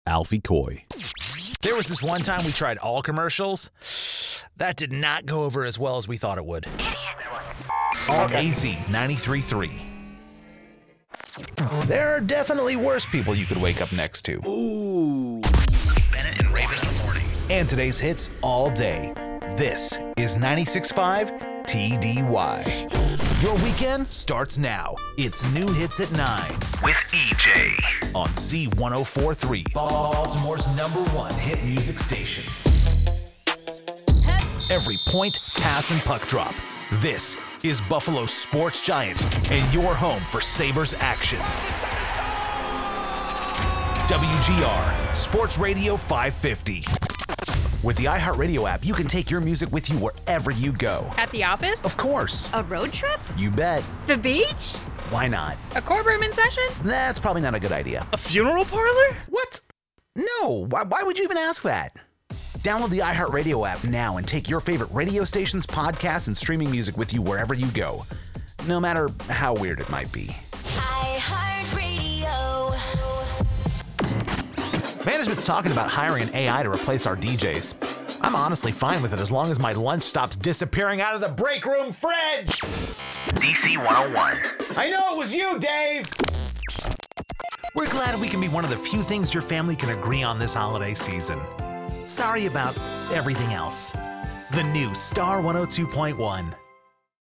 Friendly, energetic, familiar, and funny. Director tested. Client approved.
Nondescript American • New York City • Coastal/Lowland Southern • Upper Midwestern • Midland American • Western American
Young Adult
My strong suits include the casual and conversational reads that sound like they're coming from your best friend, the enthusiastic and excited reads that hype up audiences, and well-honed comedic timing for your sharp-witted copy that comes across effortlessly.